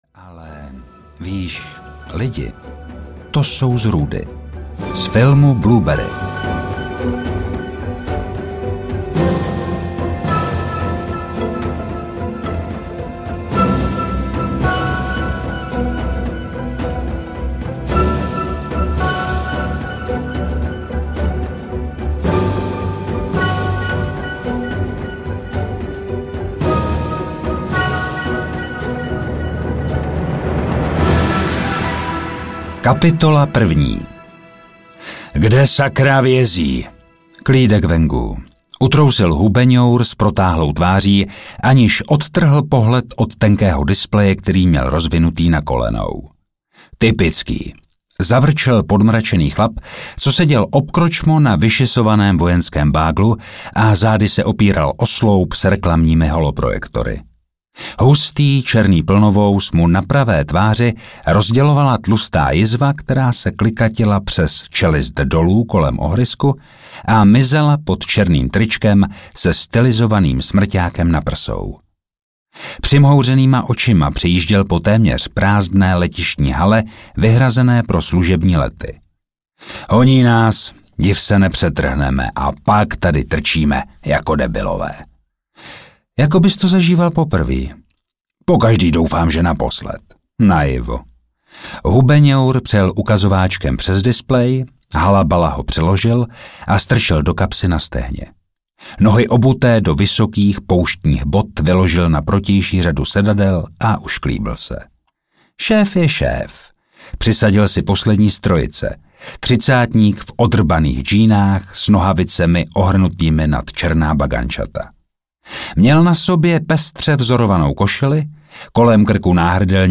Expresní zásilka audiokniha
Ukázka z knihy